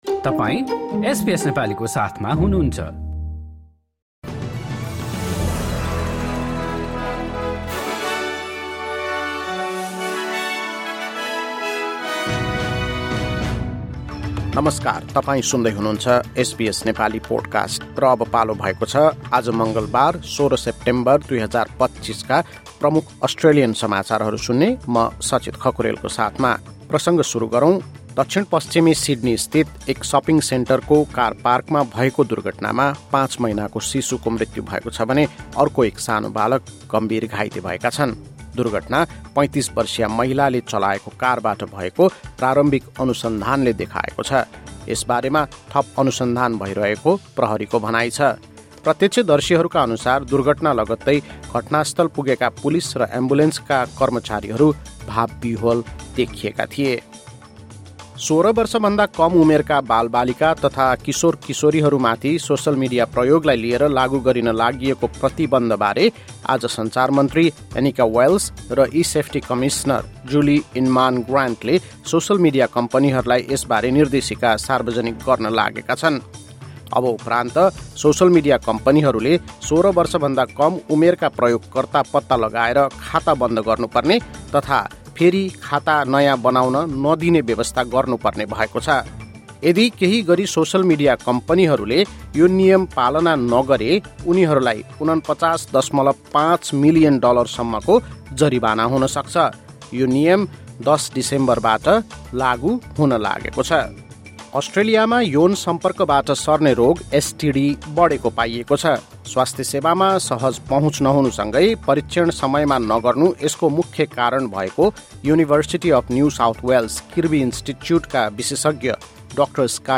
एसबीएस नेपाली प्रमुख अस्ट्रेलियन समाचार: मङ्गलवार, १६ सेप्टेम्बर २०२५